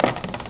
PKT_TAP.WAV